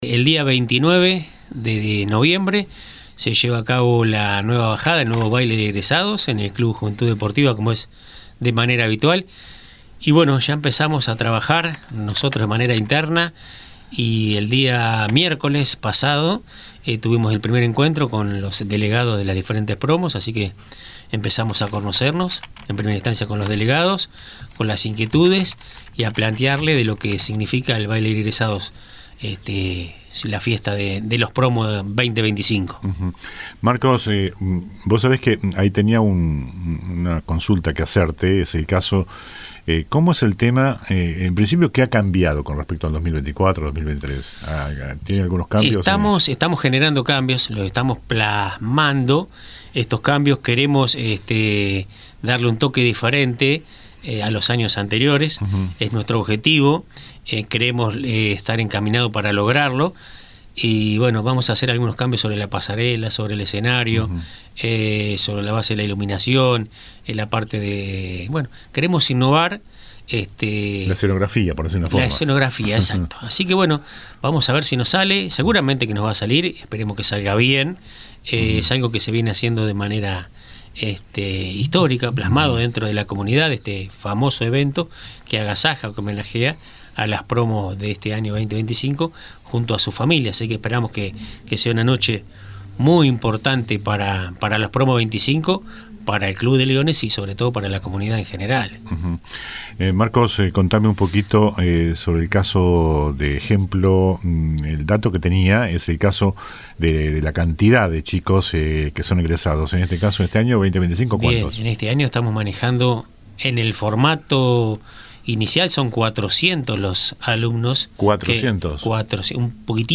Por esto invitamos al piso de LA RADIO